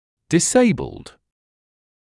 [dɪs’eɪbld][дис’эйблд]имеющий статус инвалида; ограниченный в физических возможности по состоянию здоровья в связи с увечьями и пр.; 2-я и 3-я форма от to disable